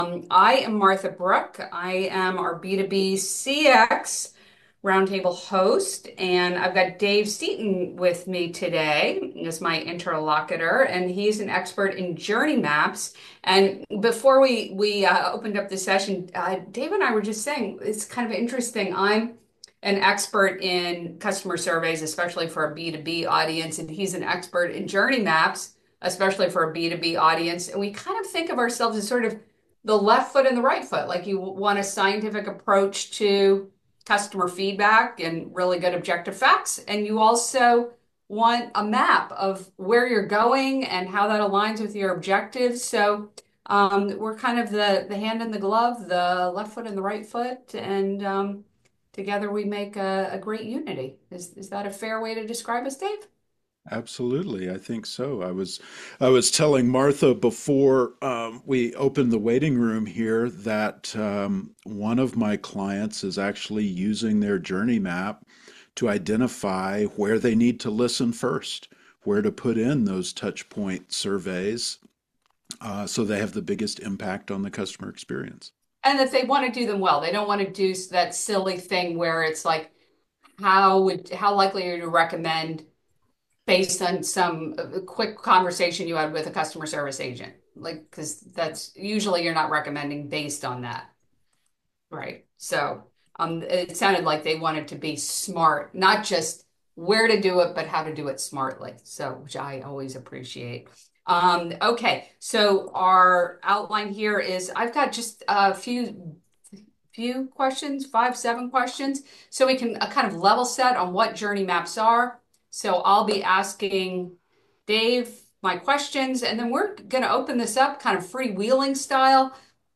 With over 75 CX practitioners joining the conversation, we dove into the heart of customer journey mapping—as a tool for creating experiences that drive ROI.